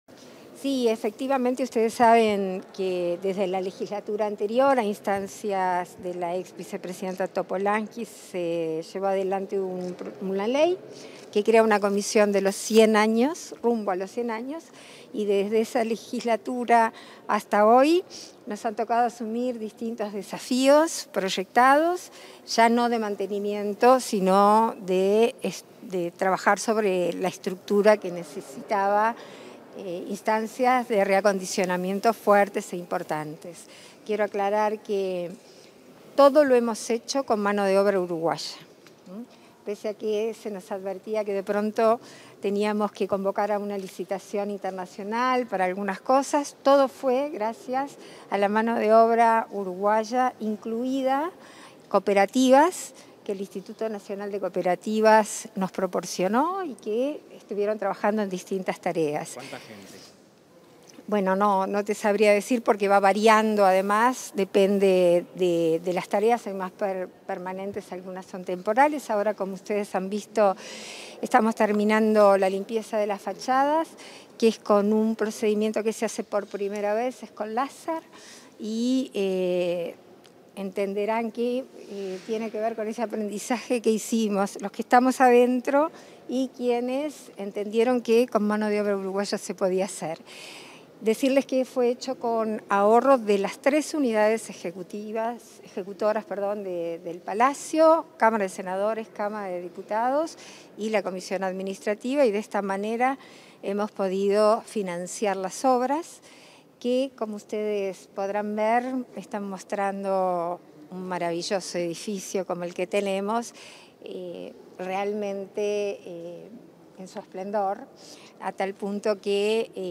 Declaraciones de la vicepresidenta de la República, Beatriz Argimón